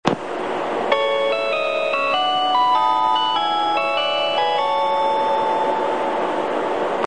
車内チャイム
「きらきらうえつ」で使用される４８５系のチャイムです。